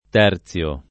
vai all'elenco alfabetico delle voci ingrandisci il carattere 100% rimpicciolisci il carattere stampa invia tramite posta elettronica codividi su Facebook terziare v. (agr.); terzio [ t $ r ZL o ], ‑zi — antiq. terzare : terzo [ t $ r Z o ]